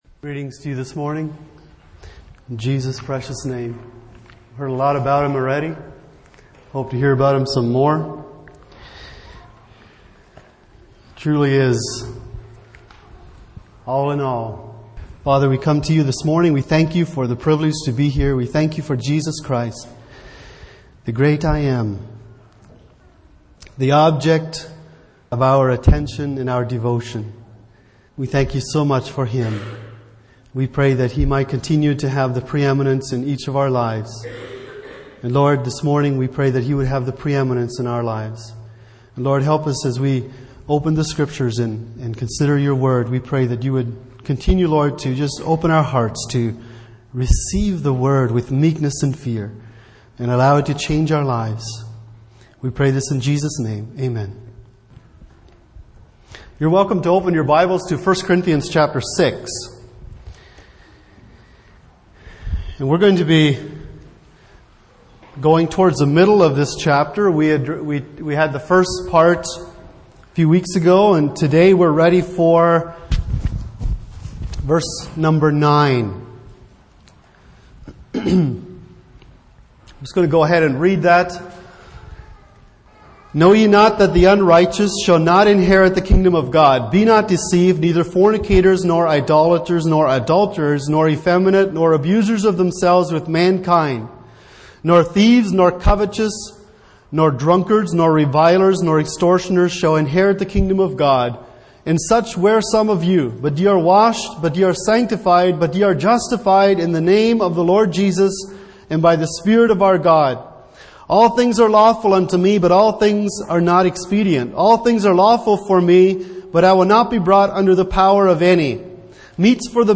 Passage: 1 Corinthians 6:9-20 Service Type: Sunday Morning